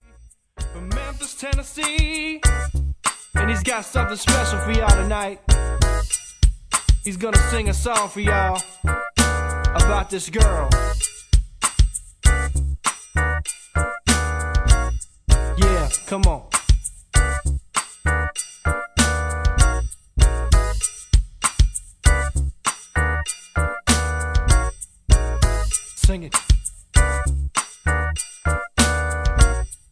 Tags: karaoke , mp3 backing tracks